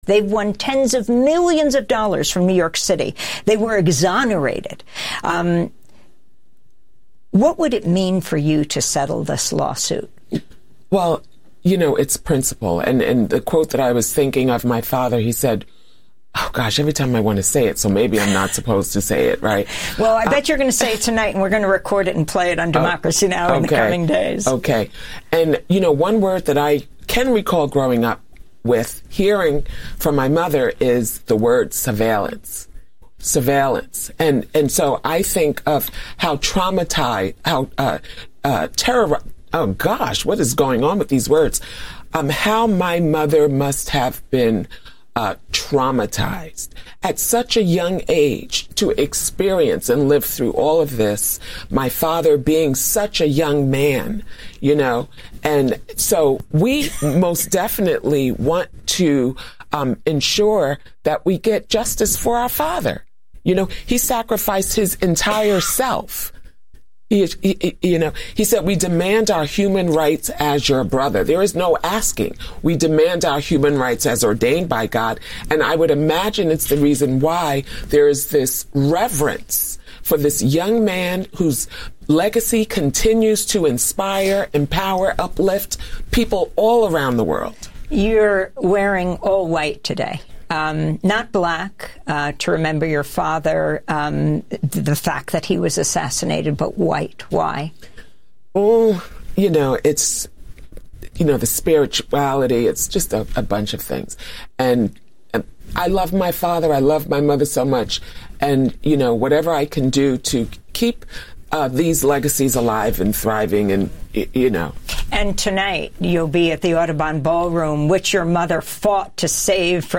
Evening News on 05/19/25